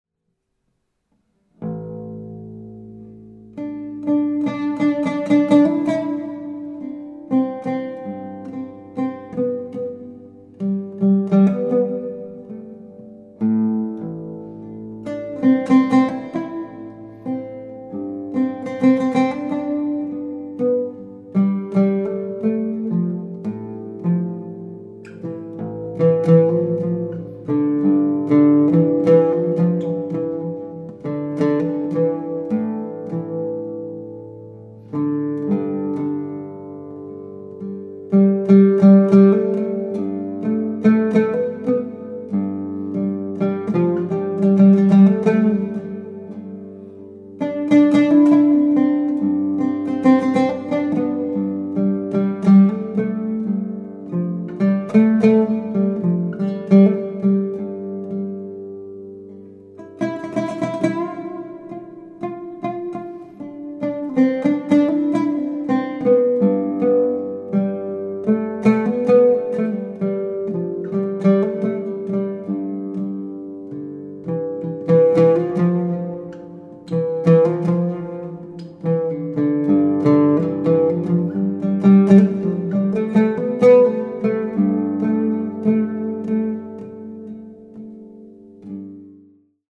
Lute , Relaxing / Meditative